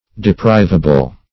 Search Result for " deprivable" : The Collaborative International Dictionary of English v.0.48: Deprivable \De*priv"a*ble\, a. Capable of being, or liable to be, deprived; liable to be deposed.
deprivable.mp3